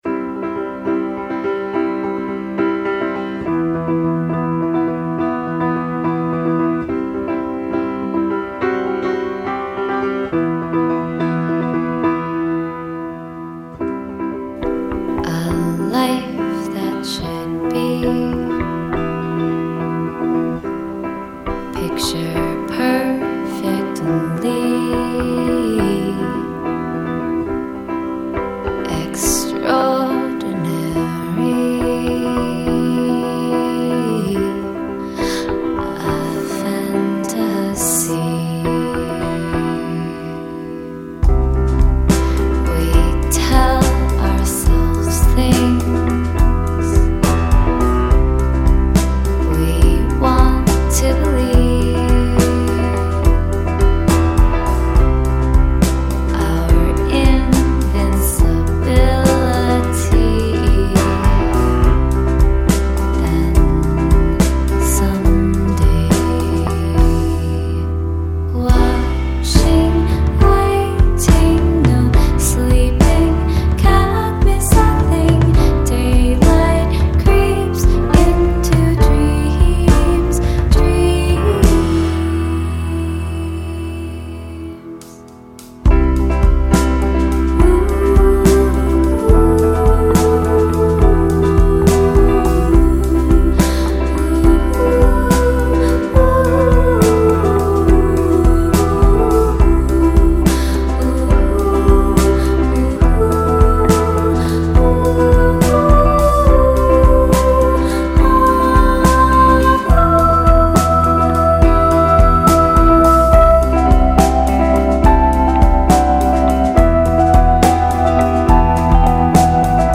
is a warm, opulent anthem that